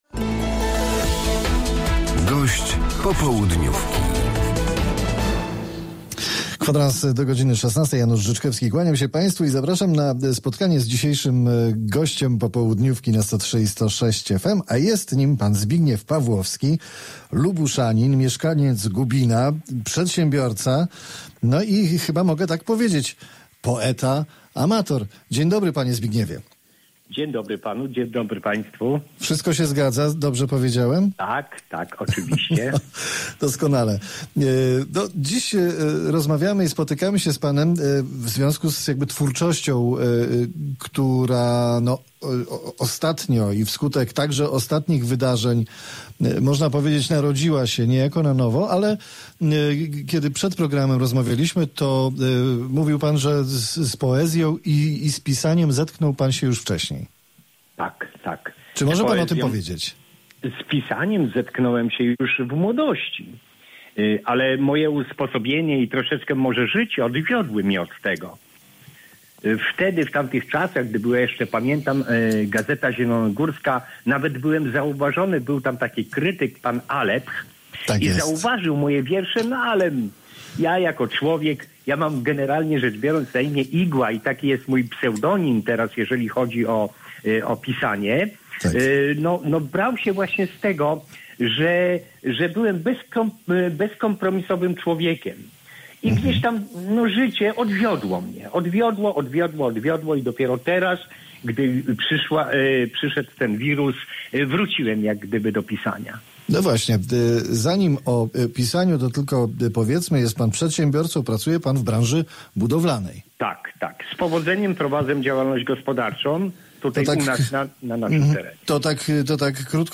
Z poetą i pisarzem amatorem rozmawia